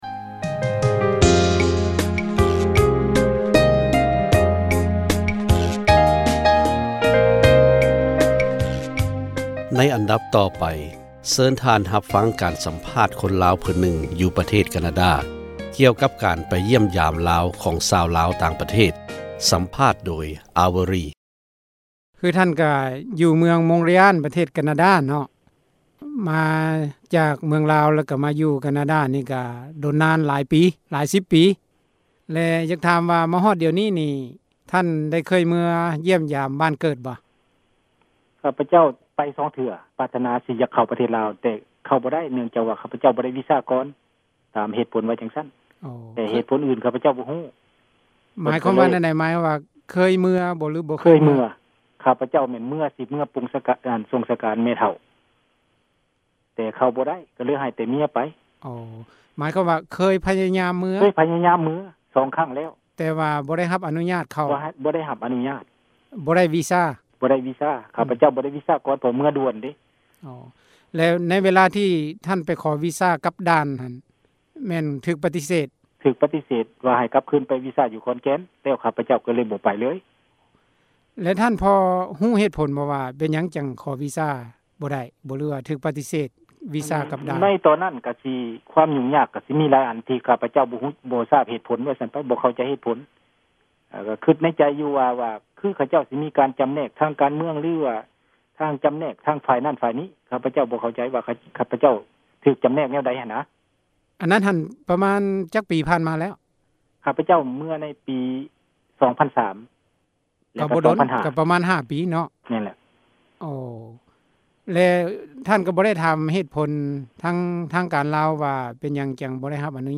ສັມພາດ ຊາວລາວ ທີ່ປະເທດການາດາ